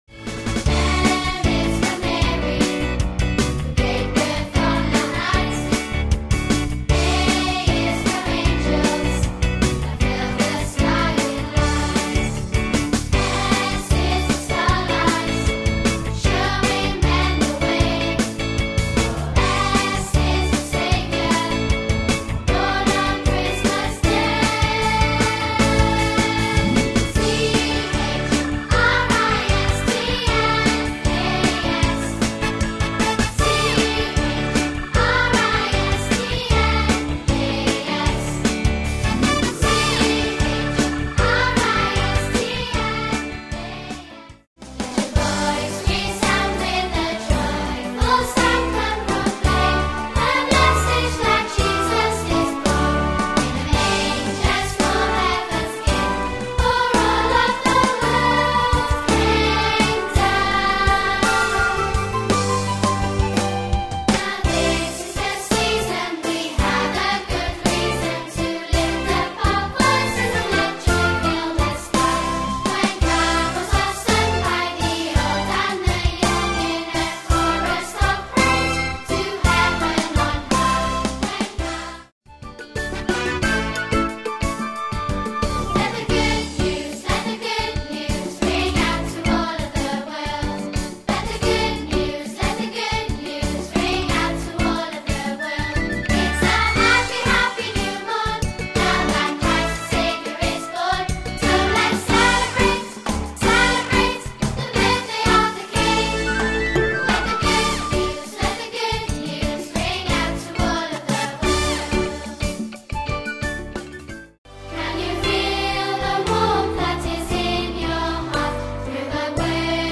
festive songs for Children